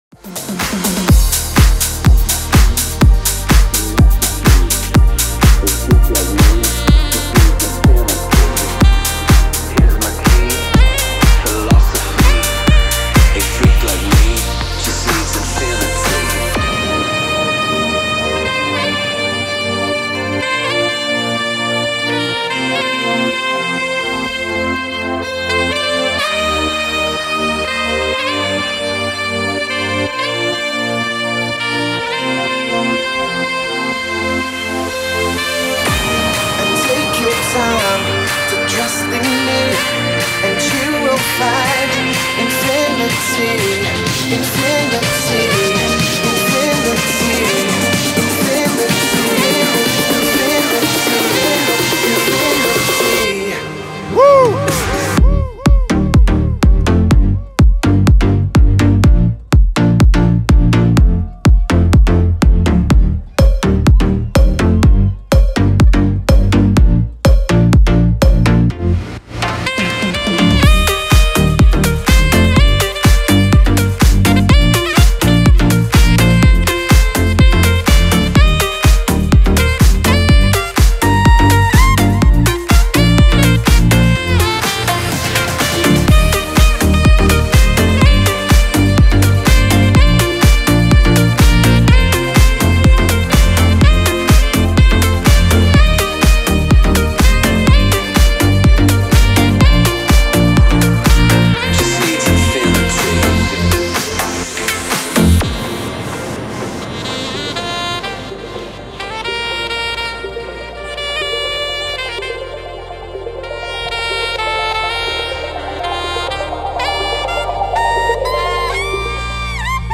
Interview radio Attitude